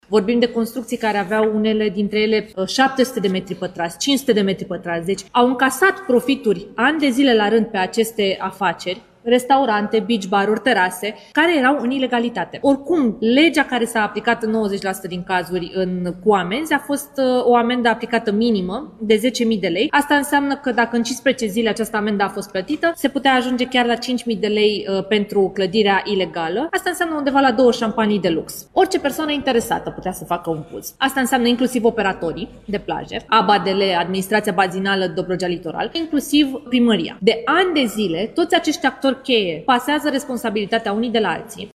Ministra Mediului, Diana Buzoianu: „Legea care a fost aplicată în 90% din cazuri, cu amenzi, a fost cu o amendă minimă de 10.000 de lei”